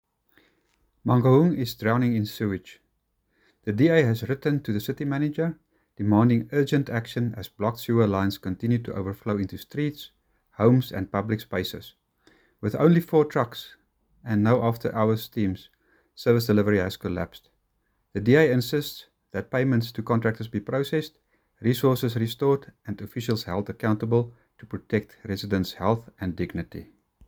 Afrikaans soundbites by Cllr Greg van Noord and